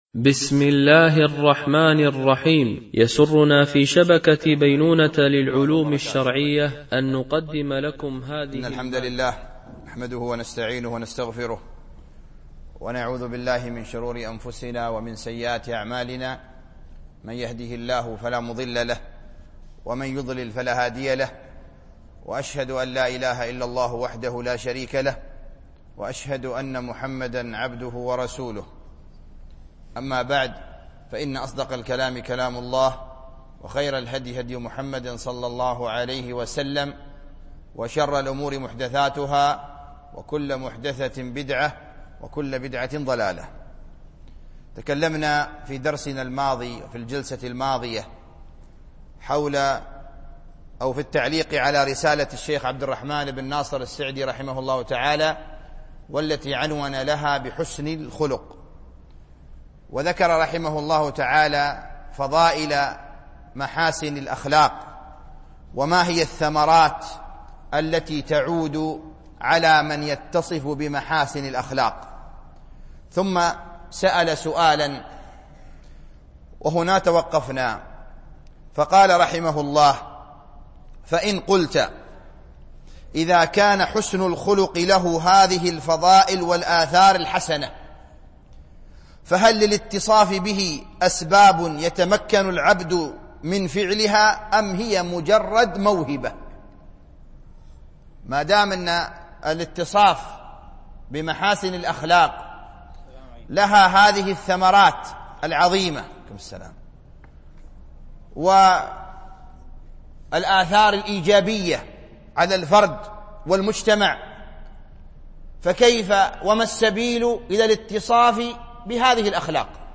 التعليق على رسالة حسن الخلق ـ الدرس الثاني